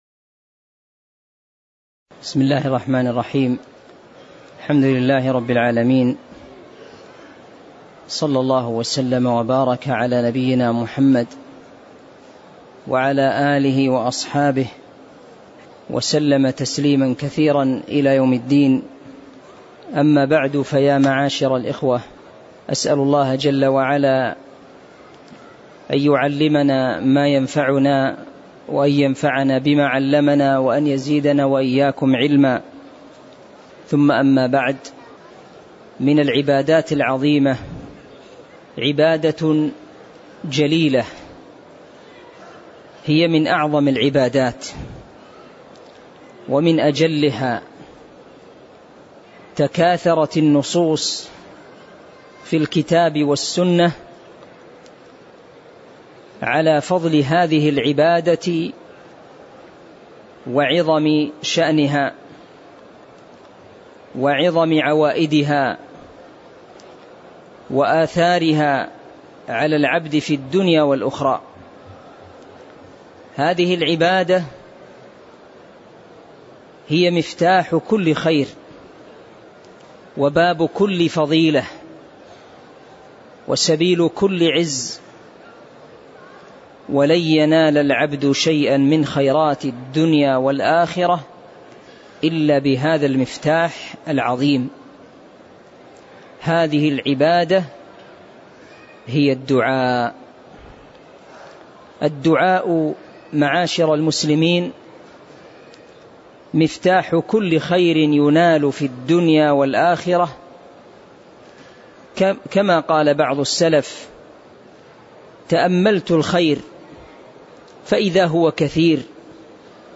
تاريخ النشر ١١ محرم ١٤٤٤ هـ المكان: المسجد النبوي الشيخ